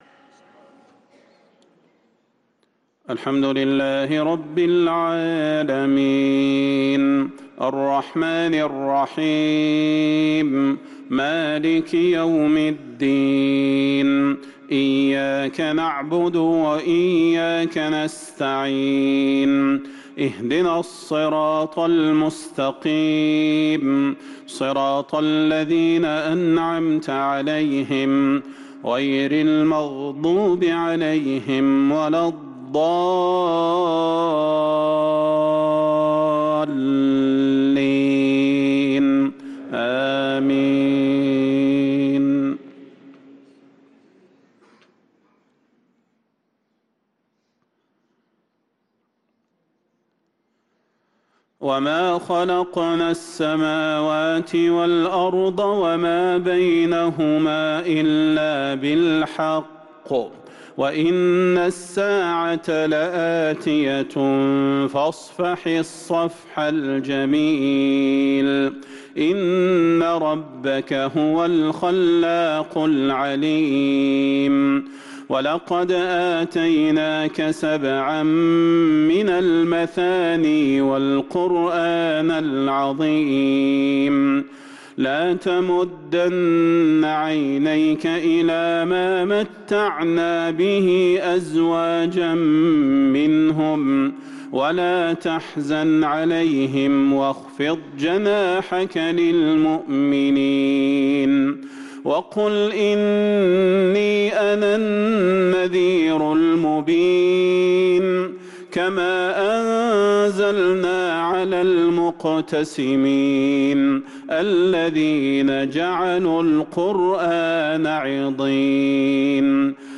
صلاة المغرب للقارئ صلاح البدير 4 جمادي الآخر 1444 هـ
تِلَاوَات الْحَرَمَيْن .